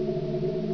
ELEVATOR.WAV